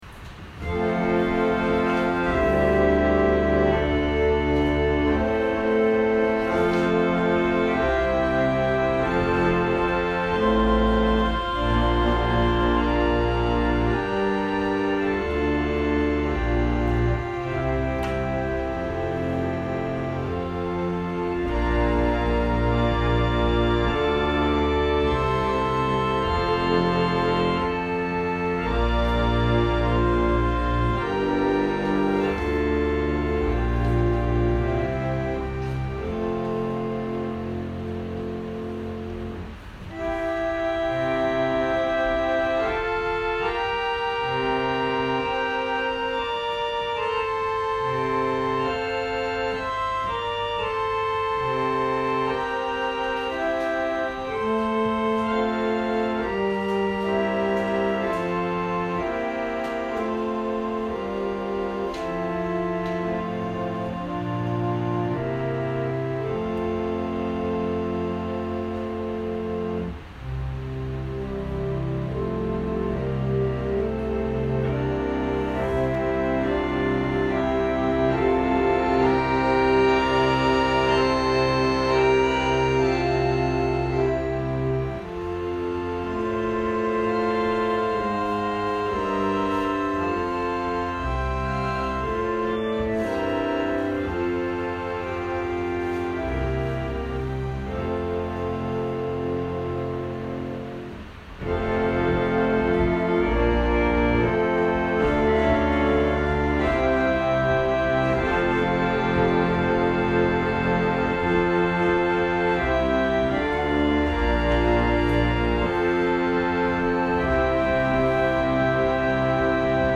2022年07月03日朝の礼拝「思い悩むな」せんげん台教会
説教アーカイブ。
音声ファイル 礼拝説教を録音した音声ファイルを公開しています。